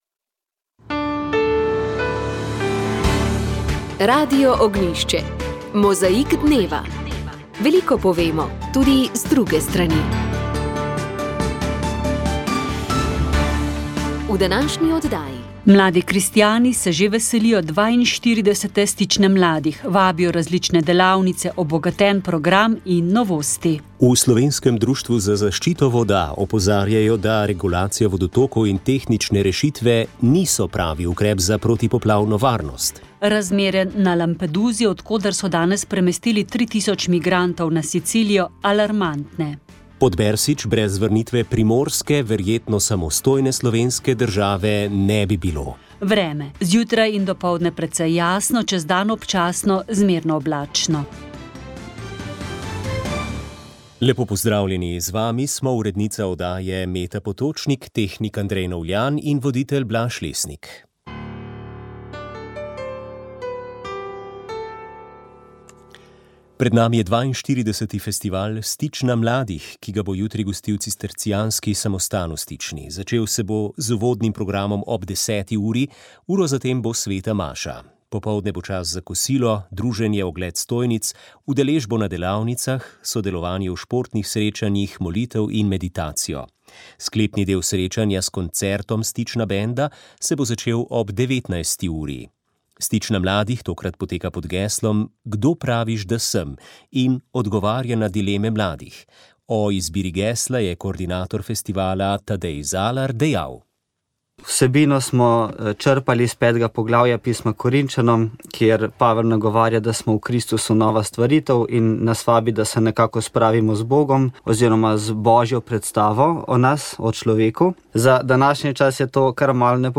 Pogovor z njo je bil posnet leta 1978